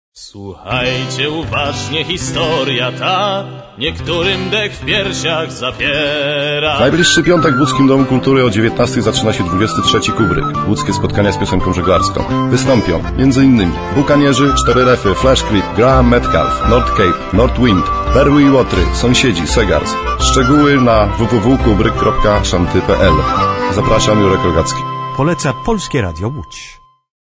spotu reklamowego.